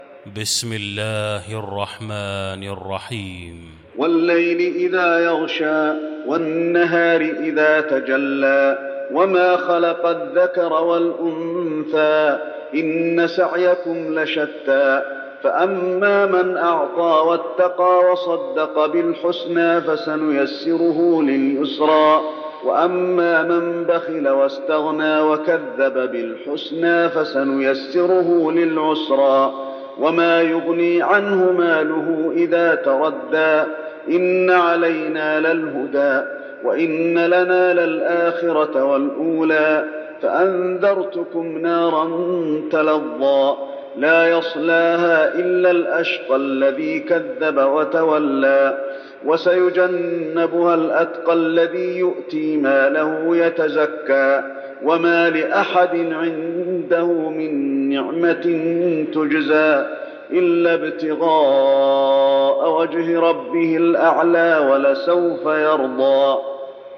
المكان: المسجد النبوي الليل